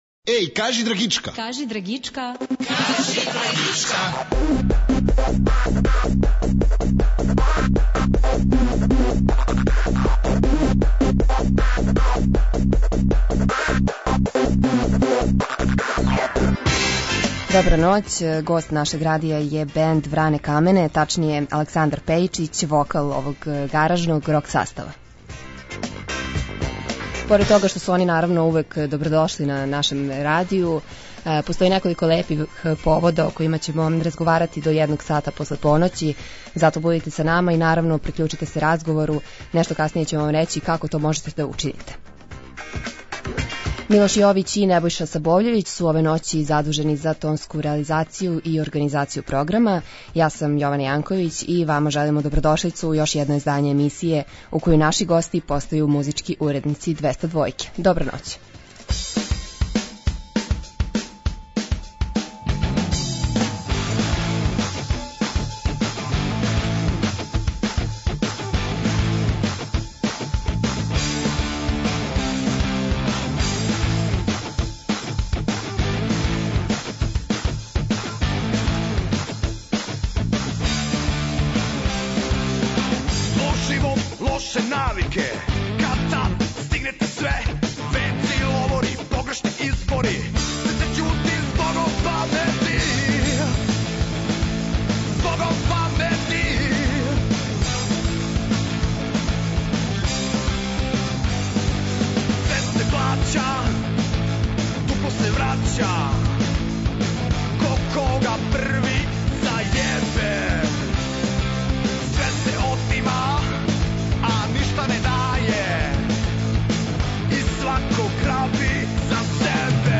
Ноћас ћемо угостити гаражни рок бенд, добро познат слушаоцима нашег радија - Вране камене.